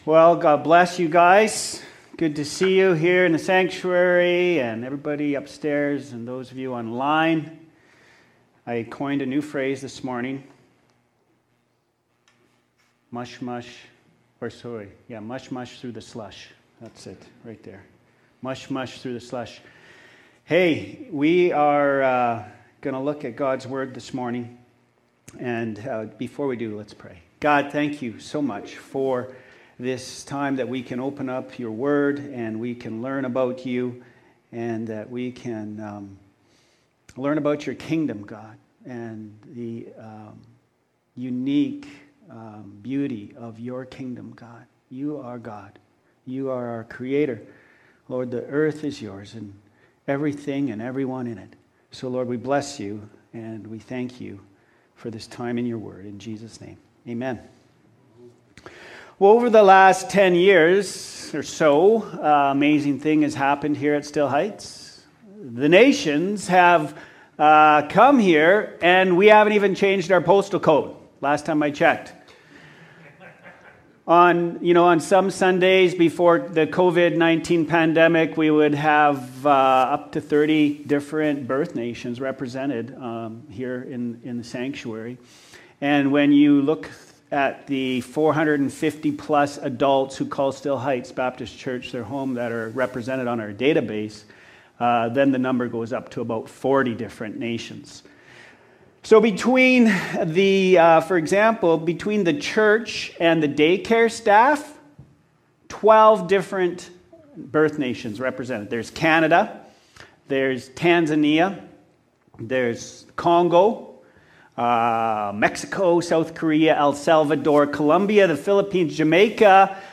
Today’s message is based on Acts 11:19-30; 12:25-3:3